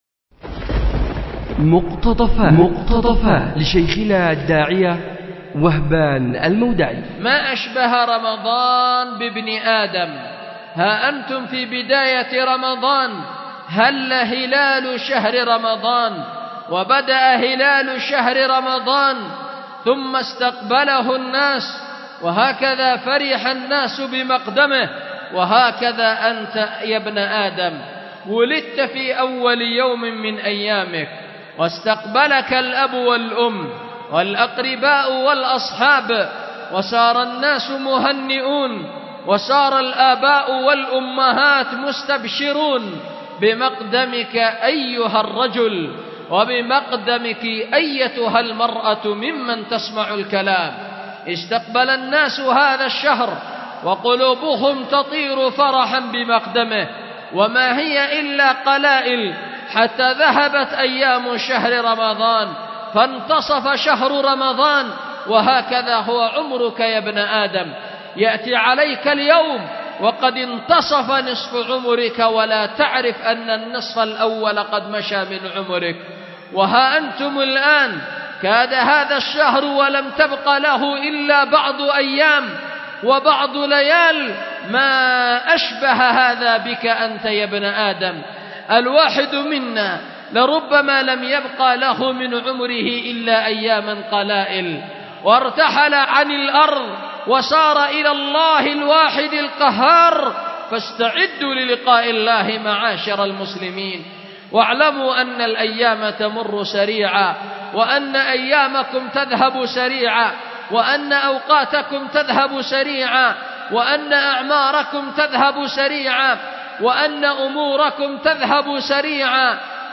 أُلقيت بدار الحديث للعلوم الشرعية بمسجد ذي النورين ـ اليمن ـ ذمار